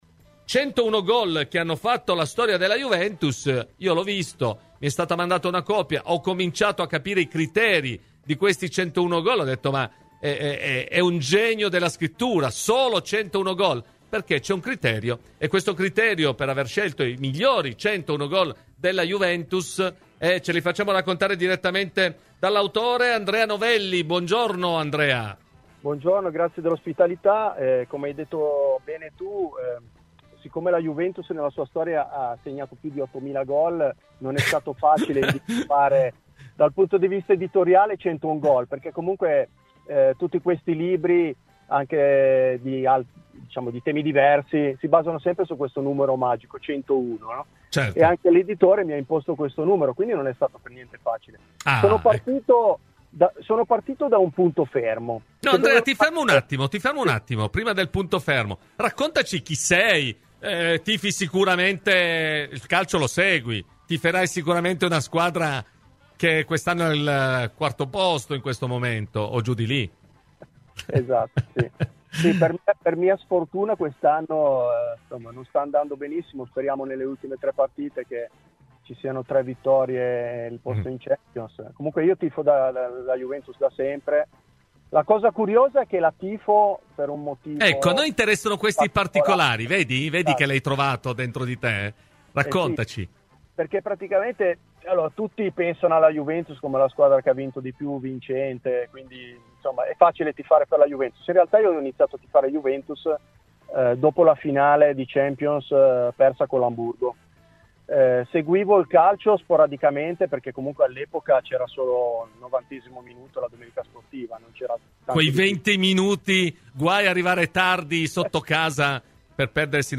Nel corso di "Cose di Calcio" su Radio Bianconera